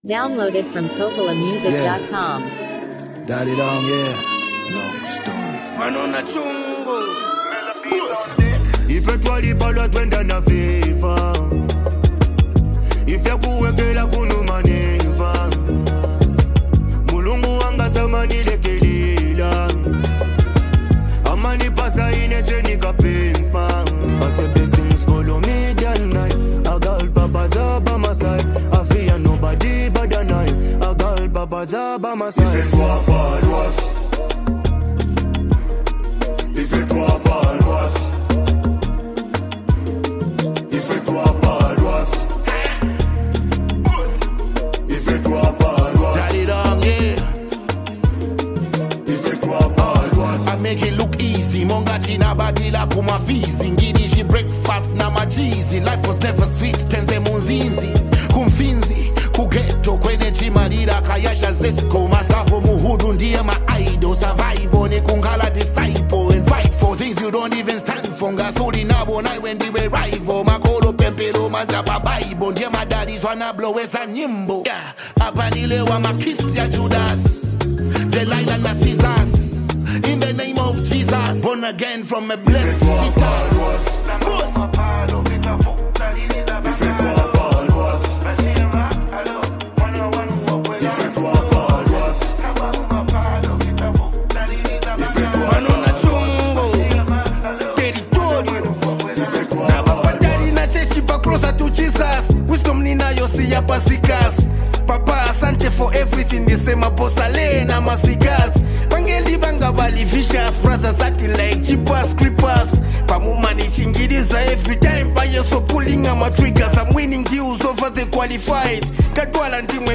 a powerful and energetic anthem
leads with his smooth yet commanding flow
steps in with sharp delivery and fearless energy